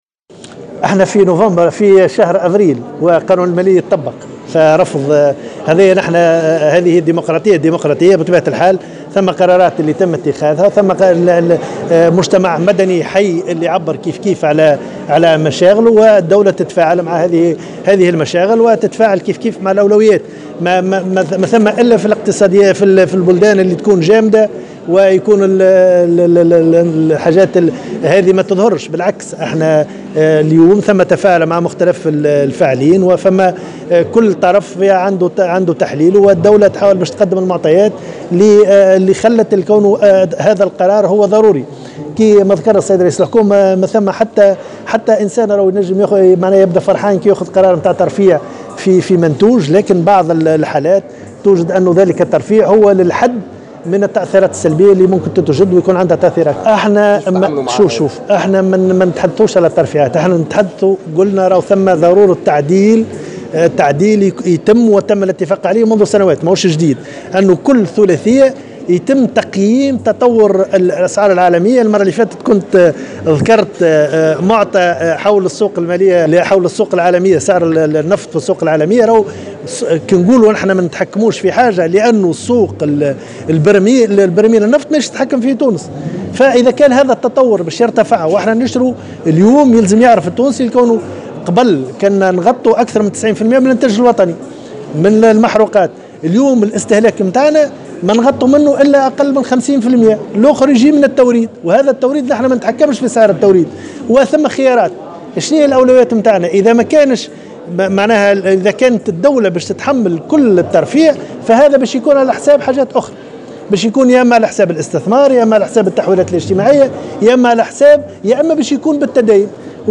قال وزير المالية رضا شلغوم في تصريح لمراسلة الجوهرة "اف ام" خلال ندوة صحفية صباح اليوم خصصت للحديث عن الانتقال من القطاع المالي إلى نظام "IFRS" إن اعتماد هذه المعايير المحاسبية الجديدة ستشمل في مرتبة أولى القطاع المالي.